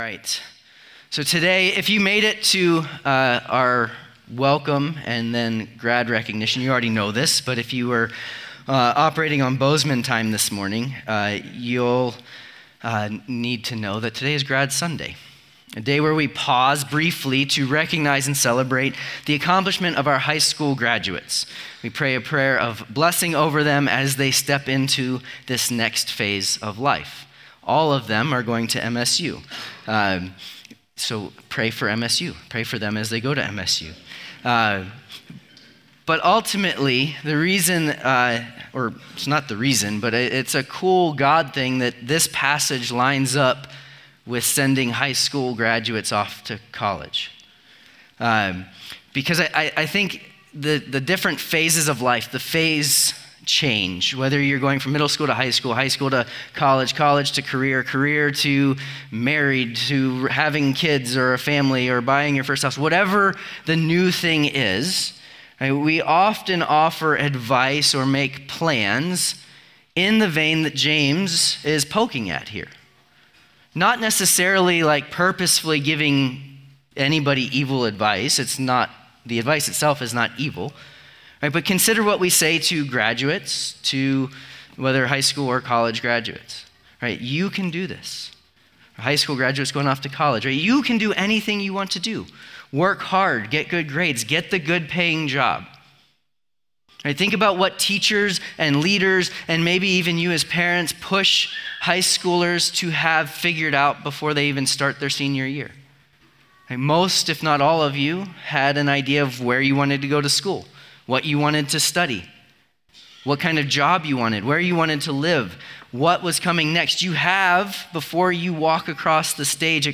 Weekly teaching audio brought to you by Redeemer Church in Bozeman, MT